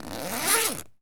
foley_zip_zipper_long_02.wav